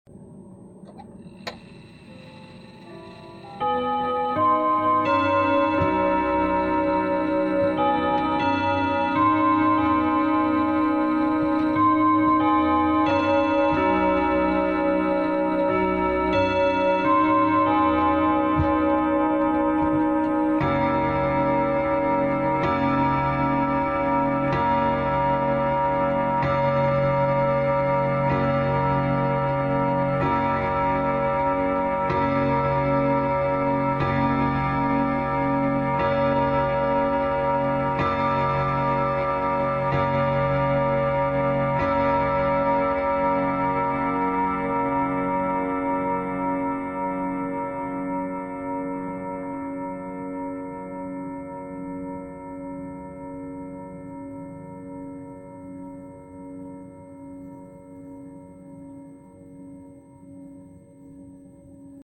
Wall of clocks, Hanoi, Vietnam